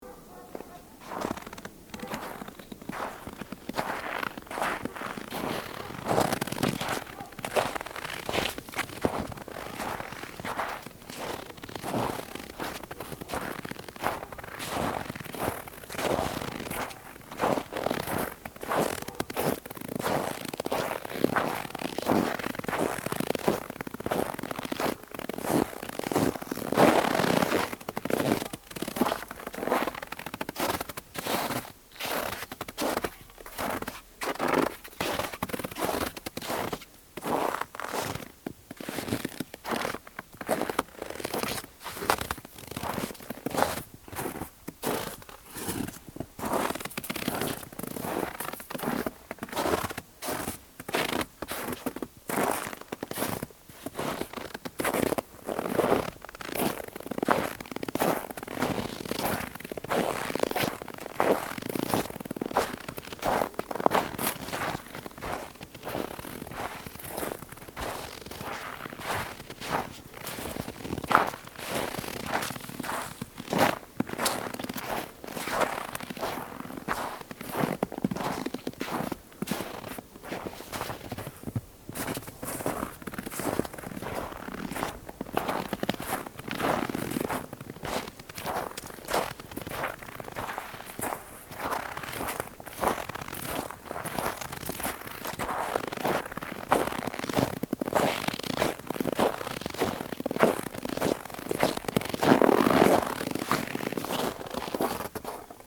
Snow
village-winter-footsteps-on-snow-snow-creaking-snow-in-winter-12071.mp3